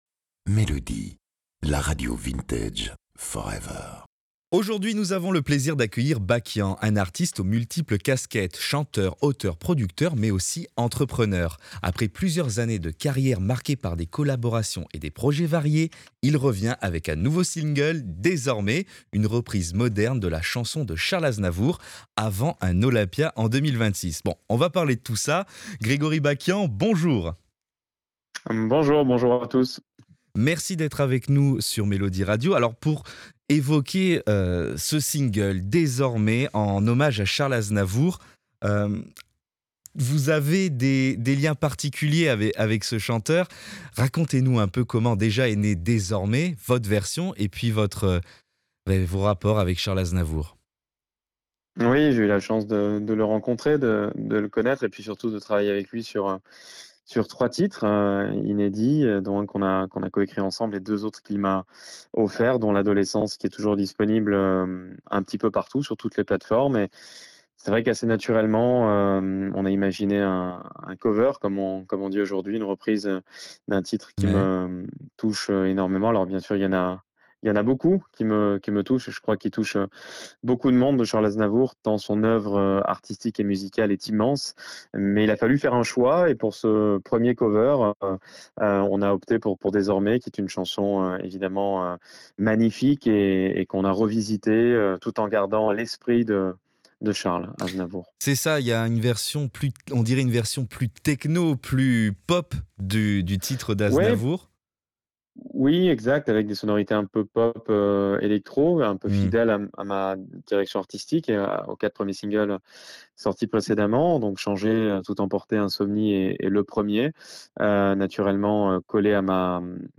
Il est venu présenter son titre pour Melody Radio.